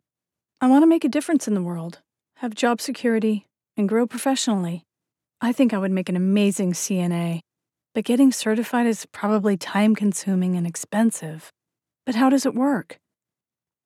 角色扮演